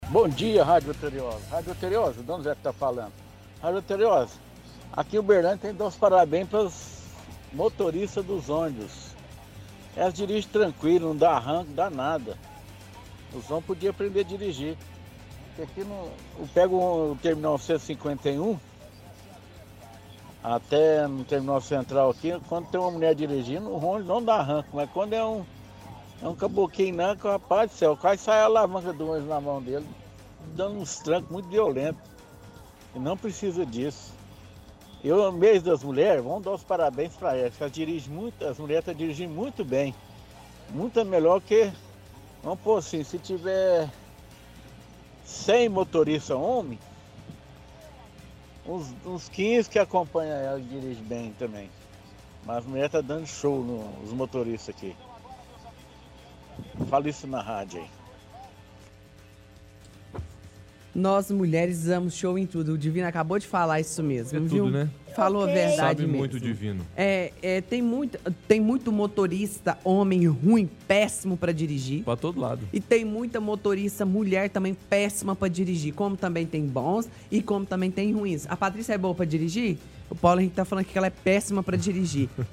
– Ouvinte critica motoristas homens do transporte público e elogia as mulheres, dizendo que elas dirigem muito melhor.